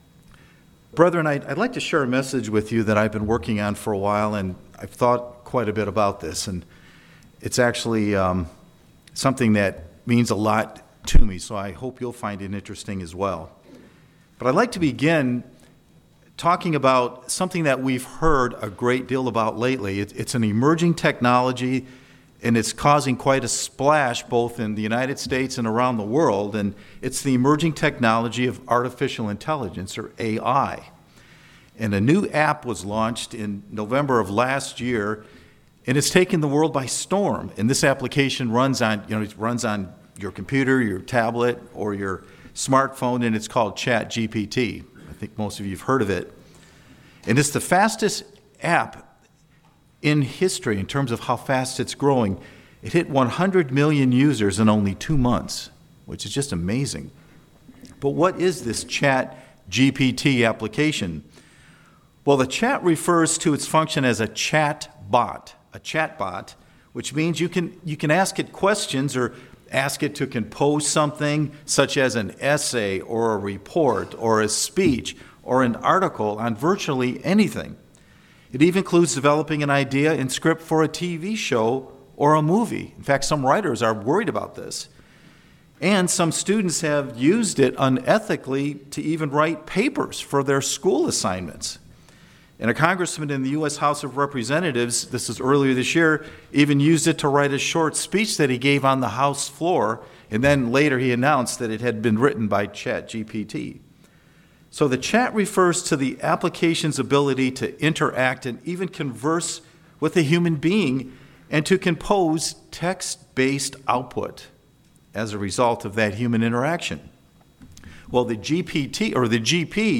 Could AI actually become truly intelligent like humans? This sermon takes a look at the reality and critical importance of spirit.